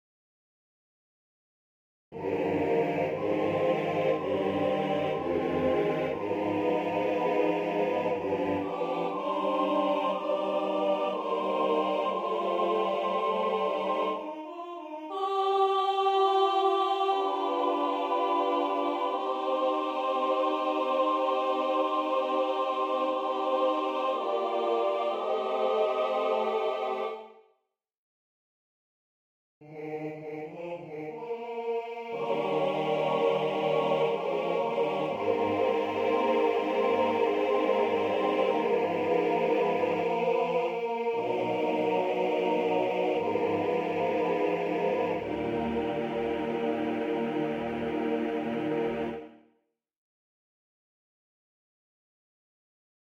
Key written in: E♭ Major
How many parts: 4
Type: Barbershop
All Parts mix: